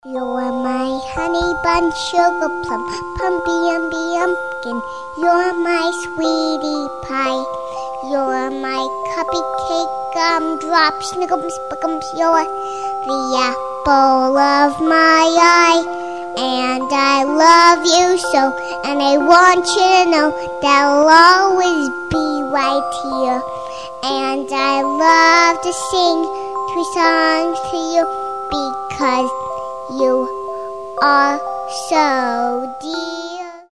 милые
детский голос
Музыкальная шкатулка